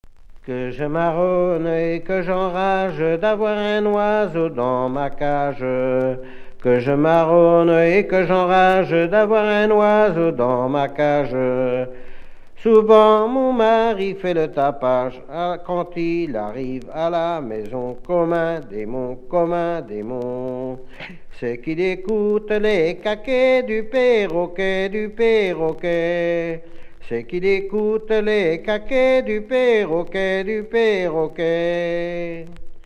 Une femme qui trompe son mari, dénoncée par un perroquet qui a tout vu Expression(s) voix seule
Genre laisse